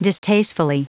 Prononciation du mot distastefully en anglais (fichier audio)
Prononciation du mot : distastefully